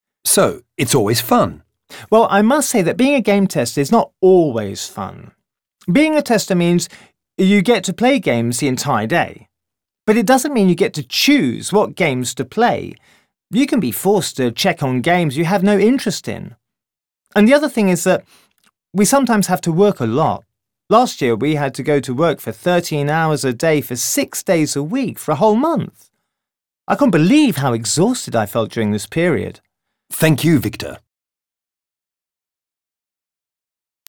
Exercise 2 : What’s your dream job (2) ? Interview